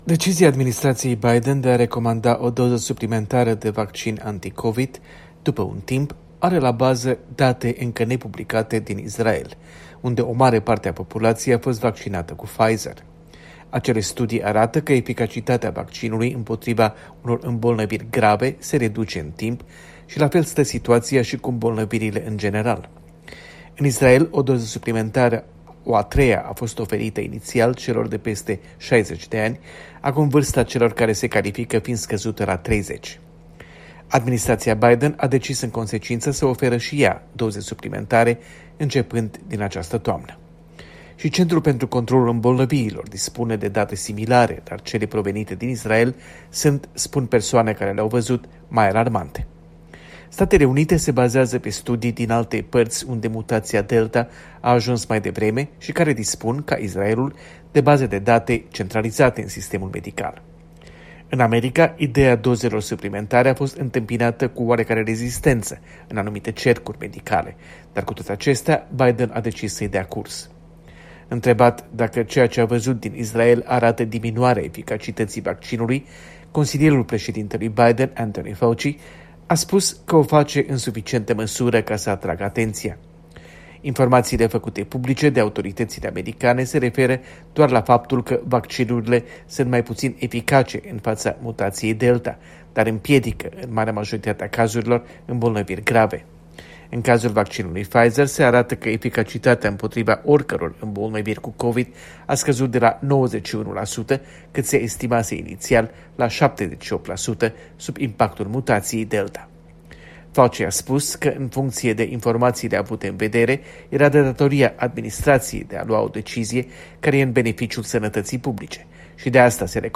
Corespondența de la Washington